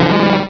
pokeemerald / sound / direct_sound_samples / cries / grimer.aif
-Replaced the Gen. 1 to 3 cries with BW2 rips.